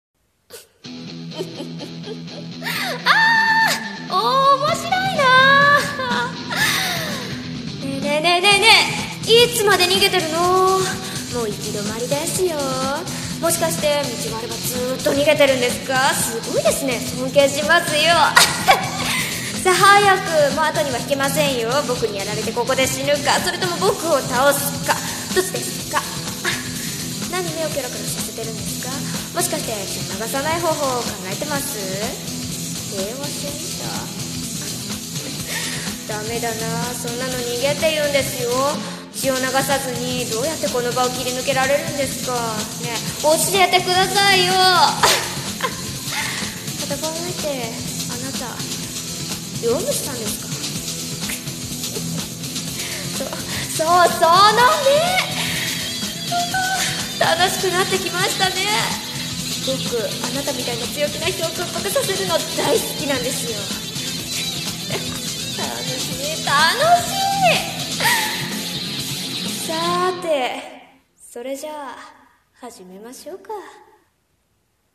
【悪役台本】行き止まり【一人声劇】